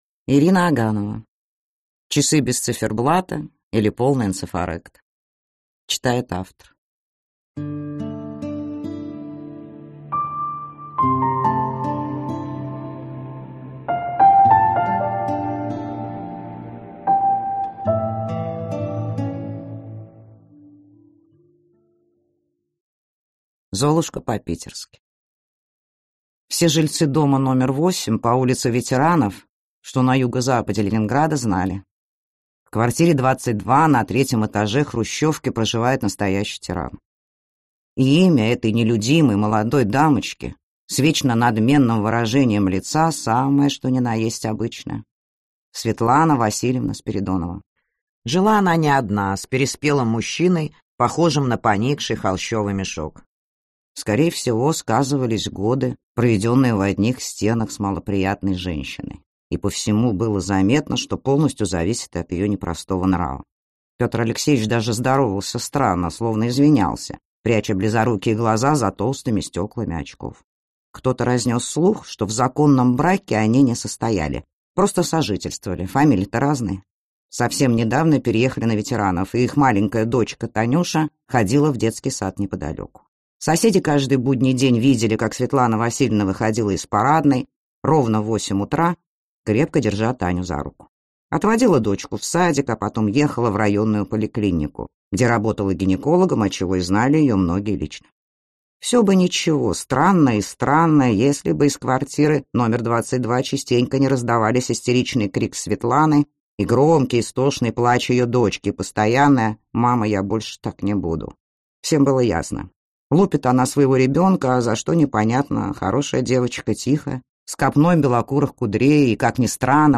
Аудиокнига Часы без циферблата, или Полный ЭНЦЕФАРЕКТ | Библиотека аудиокниг